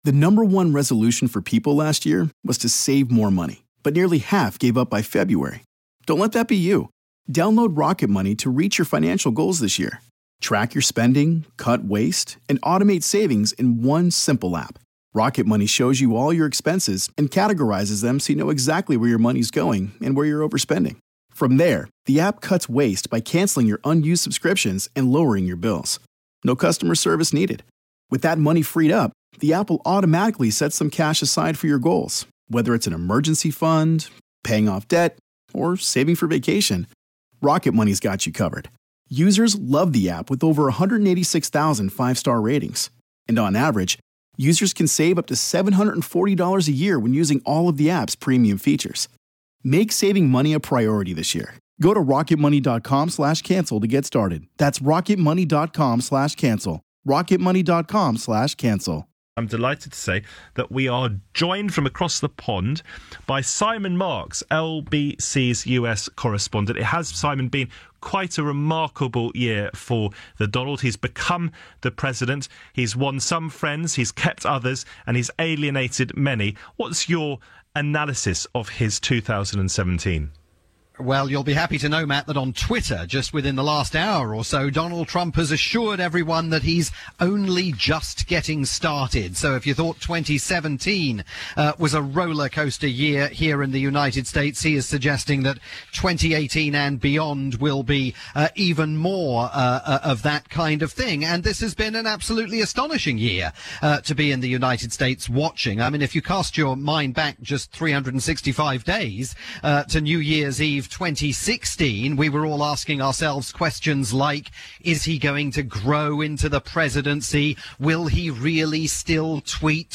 The presenter is Matt Stadlen.